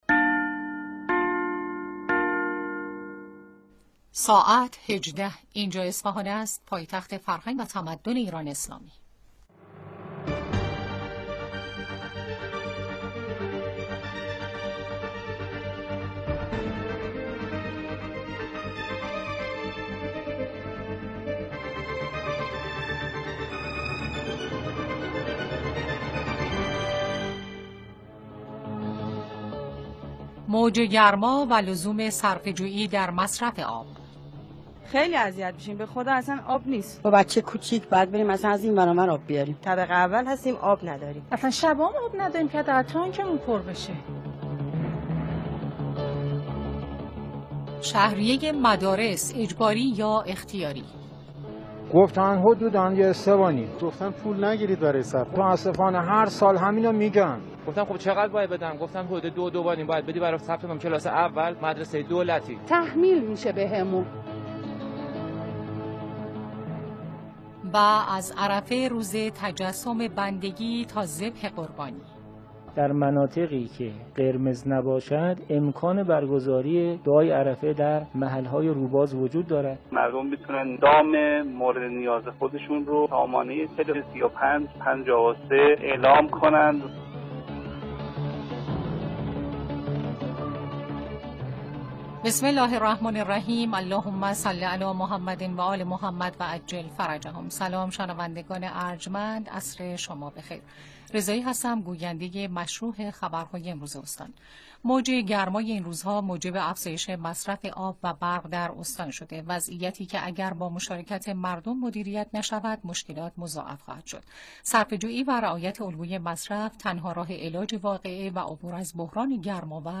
بخش خبری عصرگاهی رادیو اصفهان